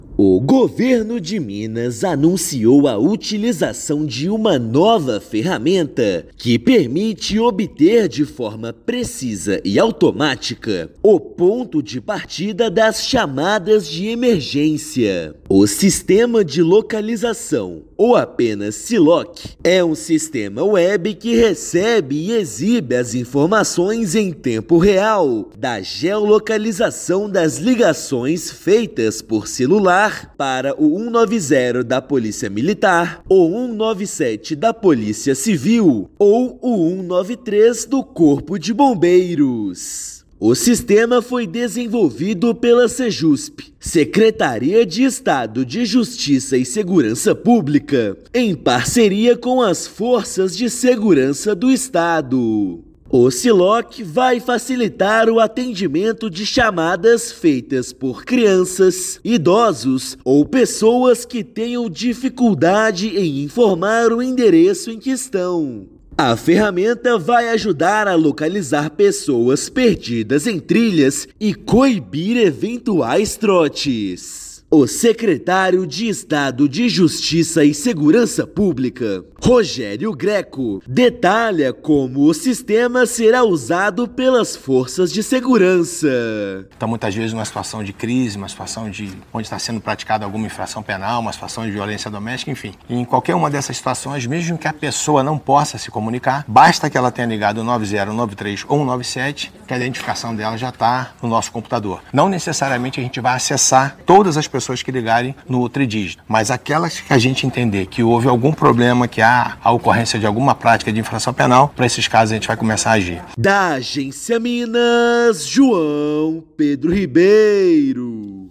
Tecnologia do Siloc permite encontrar endereço exato do celular usado para acionar os polícias Civil, Militar e do Corpo de Bombeiros. Ouça matéria de rádio.